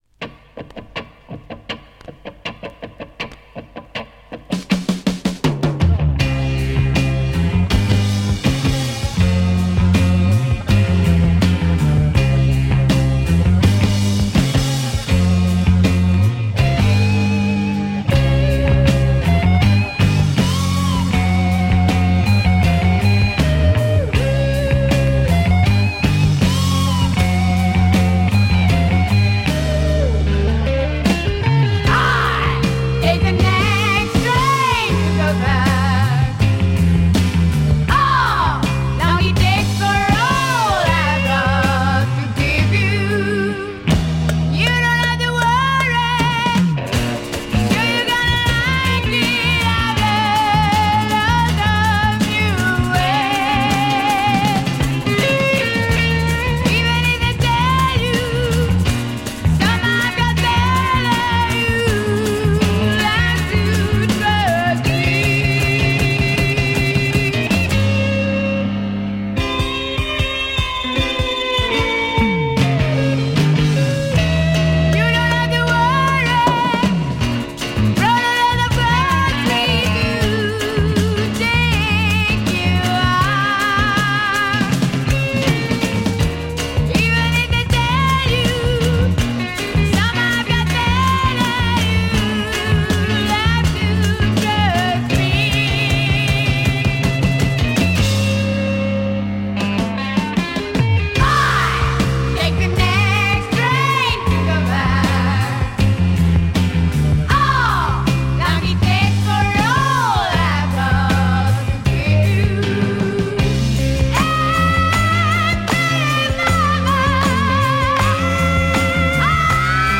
French Female heavy progster